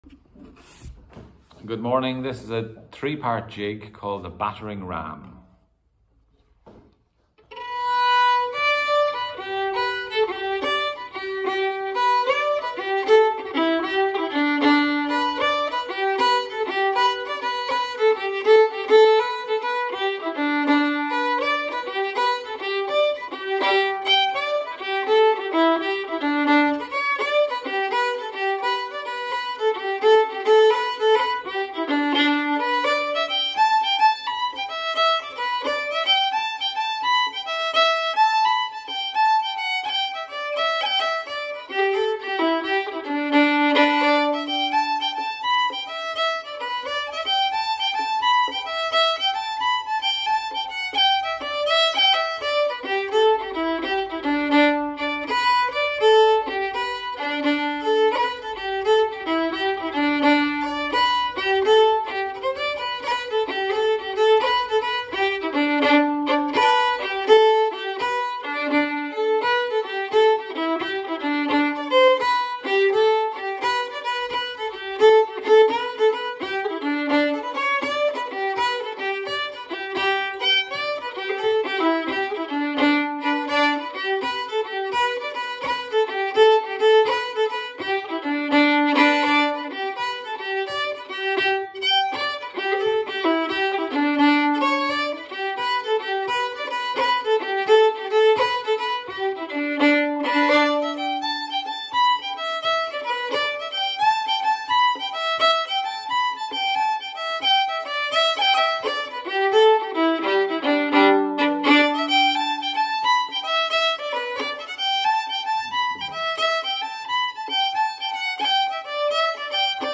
Je n’ai pas ralenti les enregistrements car la vitesse me parait déjà bien pour apprendre.
violon et
The-Battering-Ram-Jig-violon.mp3